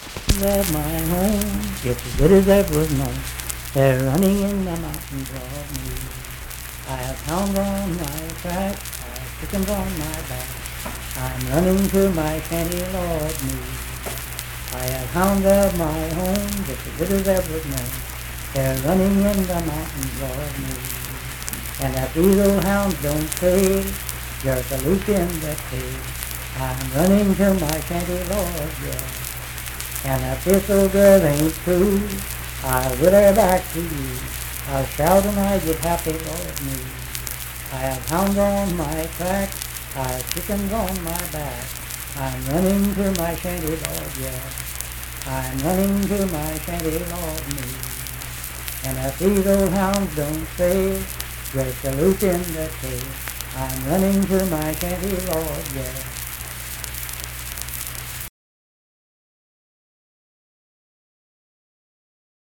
Unaccompanied vocal music performance
Verse-refrain 4(4w/R).
Voice (sung)
Sutton (W. Va.), Braxton County (W. Va.)